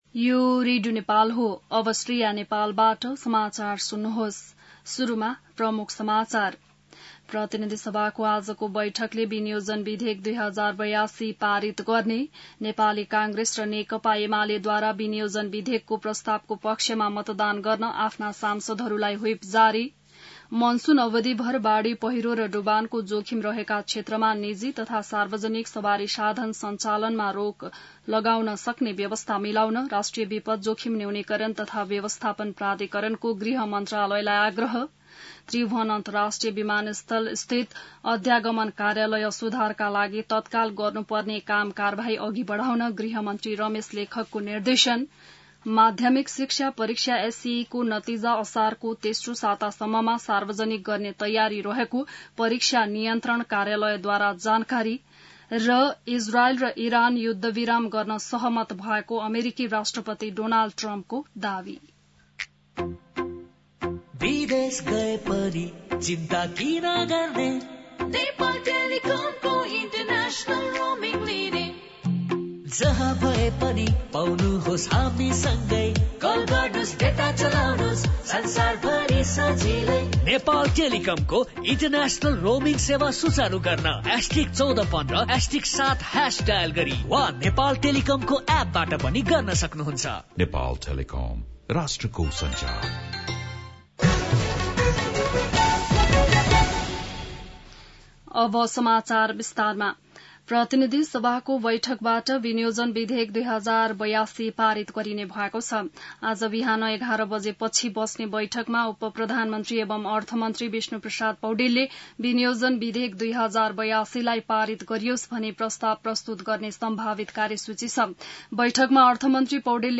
बिहान ७ बजेको नेपाली समाचार : १० असार , २०८२